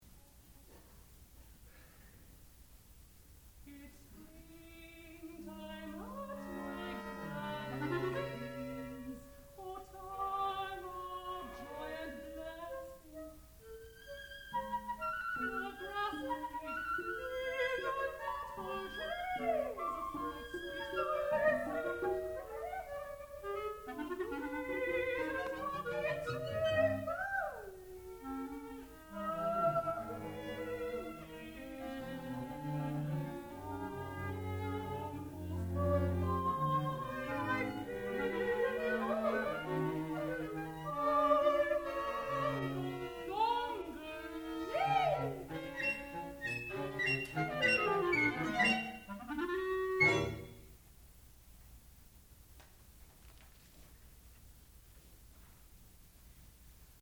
sound recording-musical
classical music
Cambiata Soloists (performer).